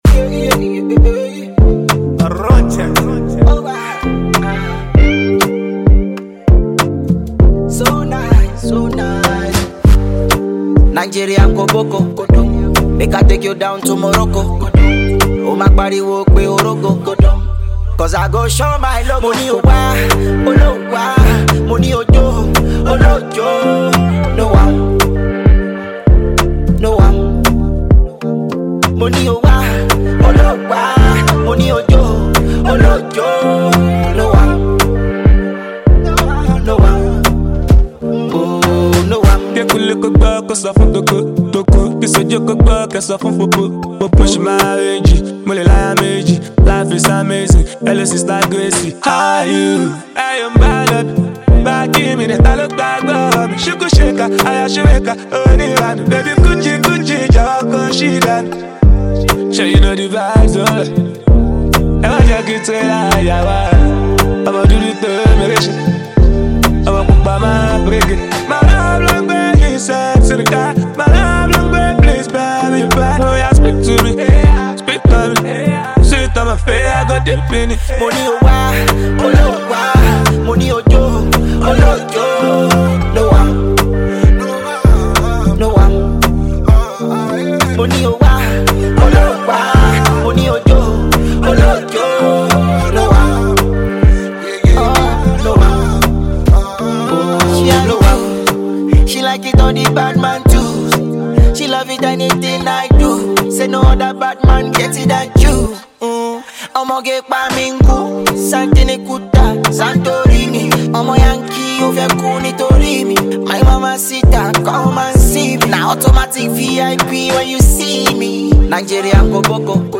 a track built for good vibes and high-energy listening.
smooth vocals and playful delivery
catchy verses and melodic flair